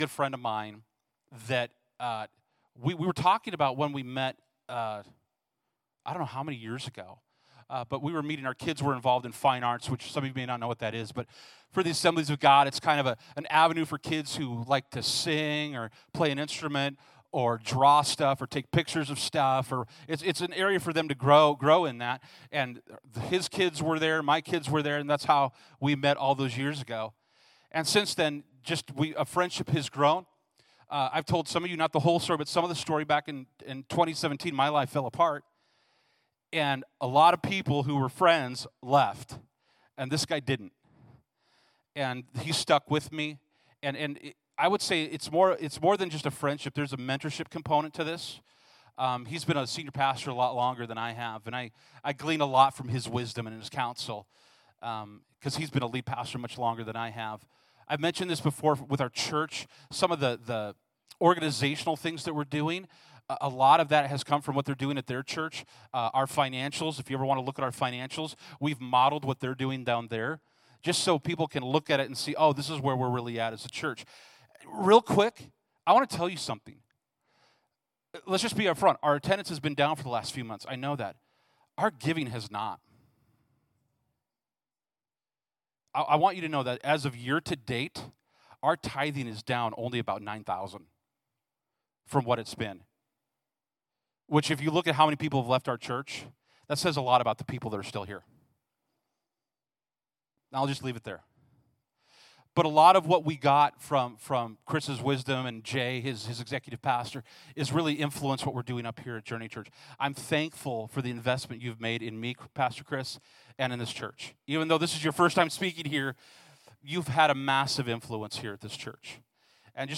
Passage: Acts 3-4 Service Type: Sunday Morning « “Audience of One